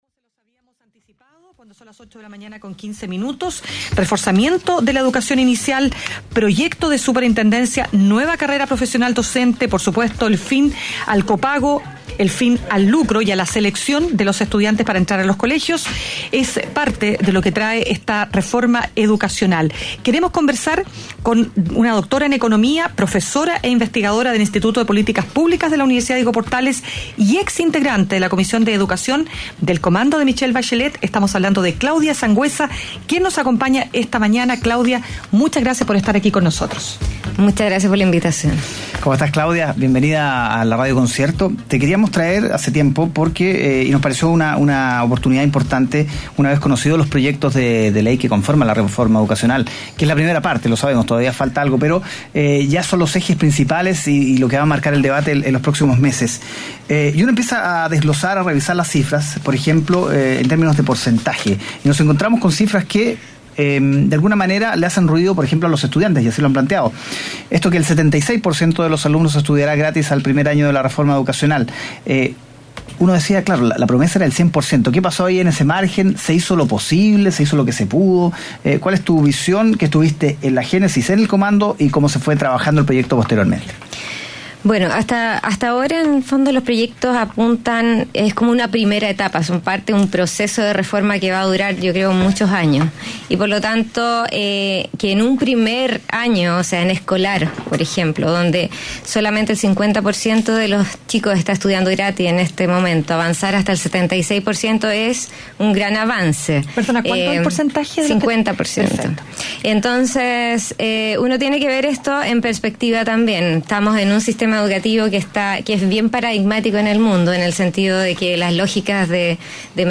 Entrevista-MSOD-20-de-mayo.mp3